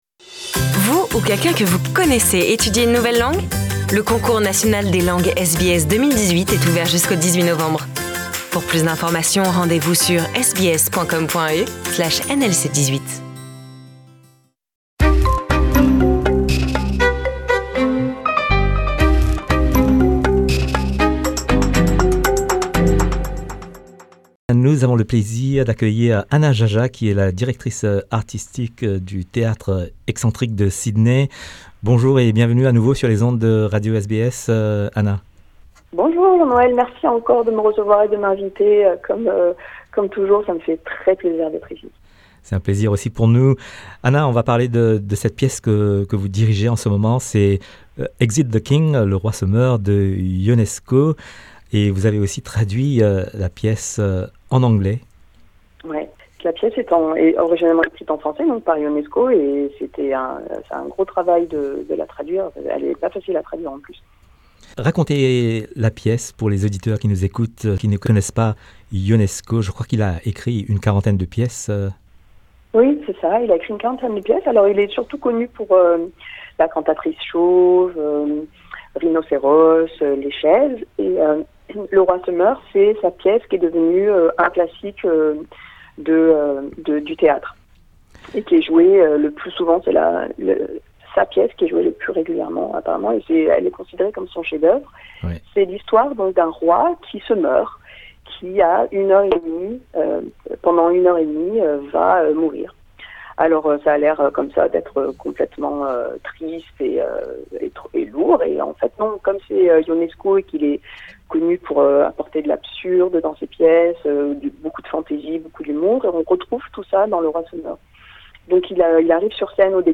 nous sommes en ligne avec elle pour faire le point.